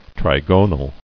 [tri·go·nal]